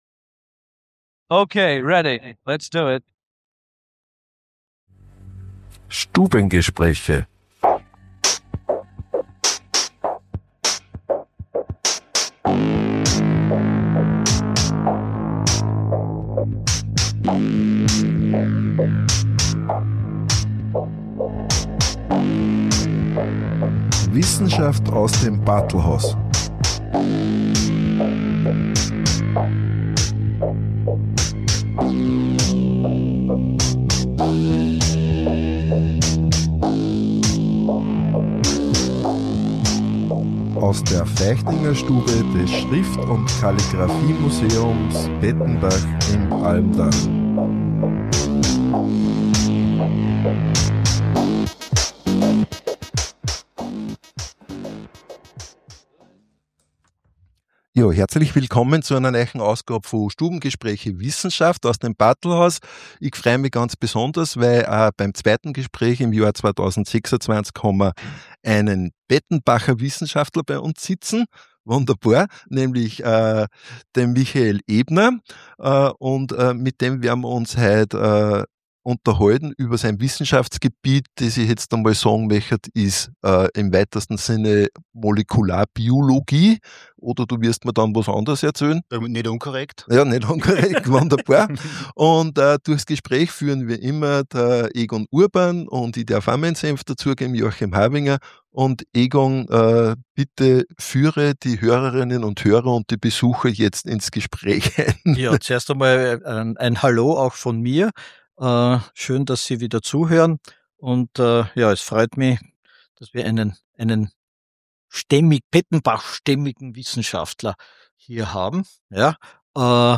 Stubengespräch Wissenschaft
Im Bartlhaus z’Pettenbach, Feichtingerstube – um 11 Uhr,